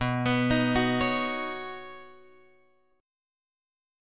Badd9 chord {7 x 9 8 7 9} chord